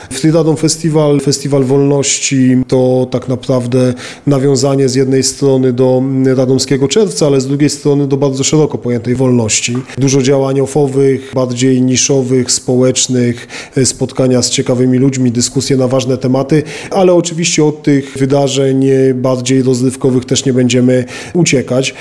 Po raz drugi w plebiscycie udało się pozyskać 200 tysięcy na organizację FREE(RA)DOM FESTIVAL. O szczegółach mówi Mateusz Tyczyński, wiceprezydent Radomia: